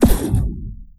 ShotFuturistic.wav